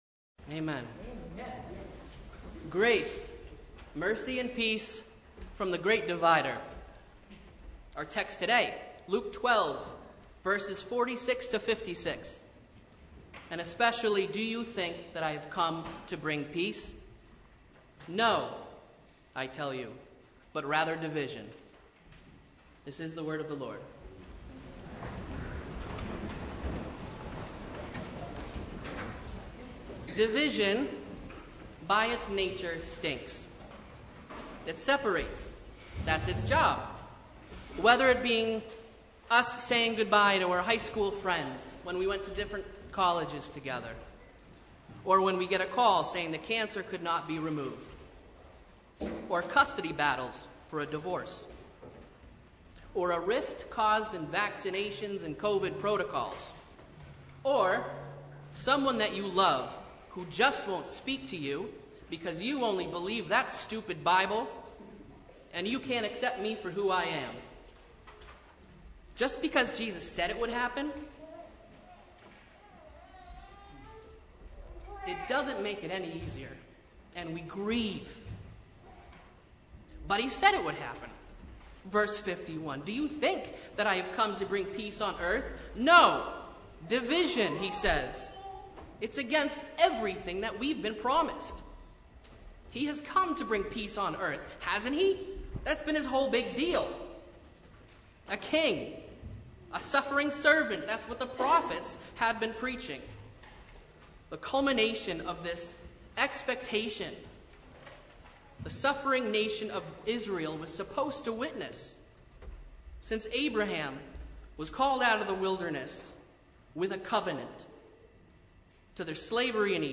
Sermon from The Second Sunday in Martyrs’ Tide (2022)
Sermon Only